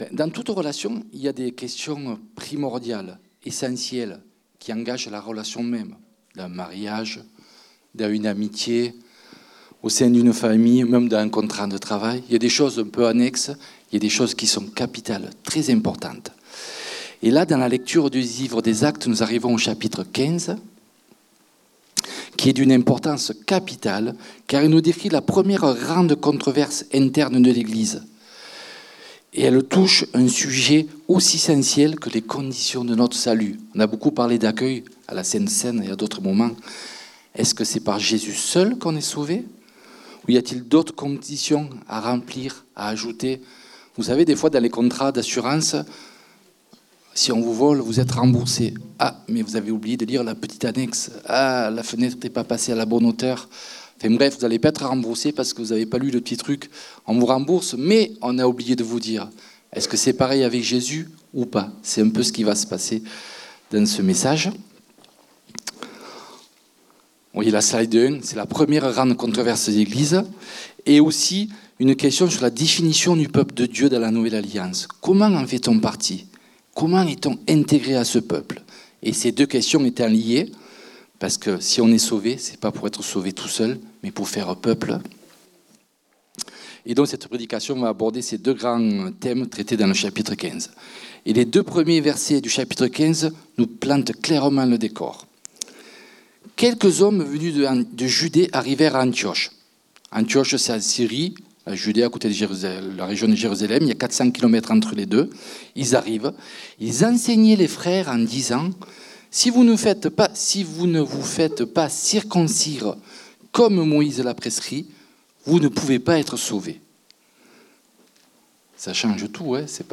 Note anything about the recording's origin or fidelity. Culte du dimanche 17 novembre 2024, prédication